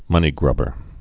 (mŭnē-grŭbər)